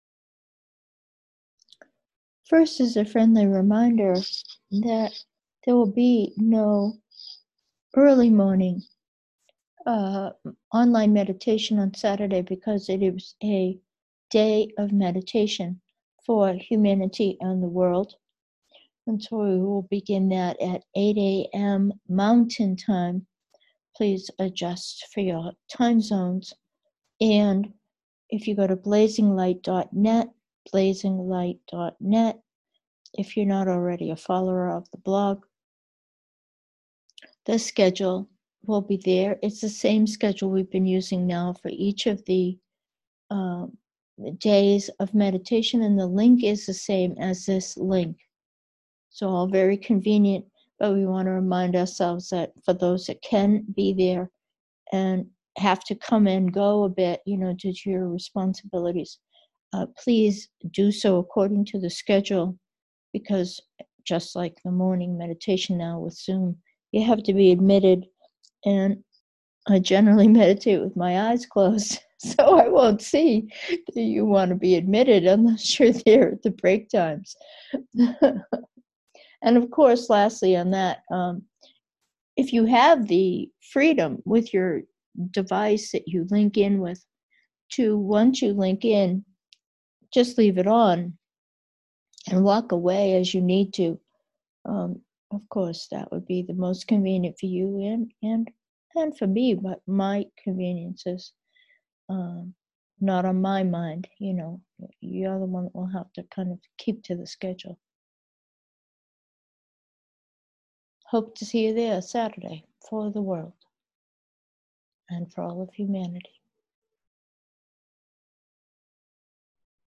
Meditation: awakening, ordinary